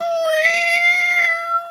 cat_2_meow_long_04.wav